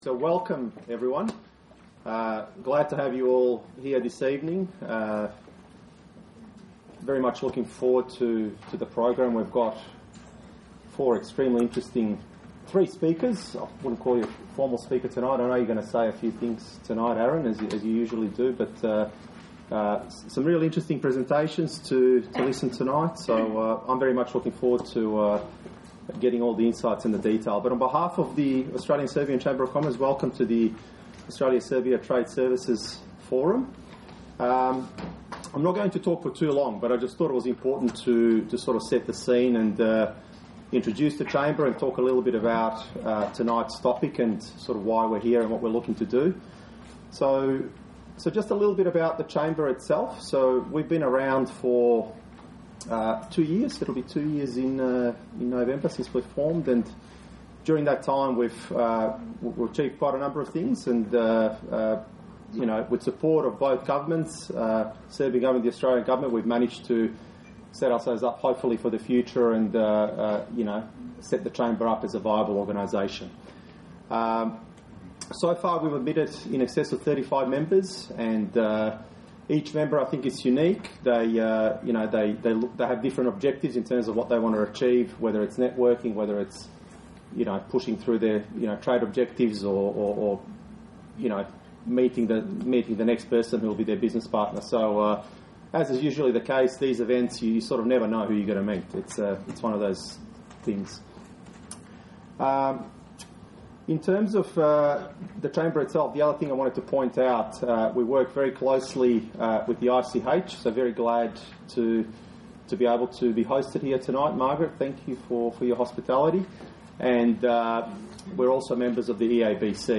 У уторак 23. октобра у Међународном центру за привредну сарадњу у Мелбурну (The International Chamber House of Victoria) одржан је форум у организацији Аустралијко-српске провредне коморе (Australian Serbian Commerce Chamber), на коме jе промовисана област информационих технологија у Србији као и побољшање трговине измећу Србије и Аустралије.
Forum "Creating Possibilities" Source: sbs serbian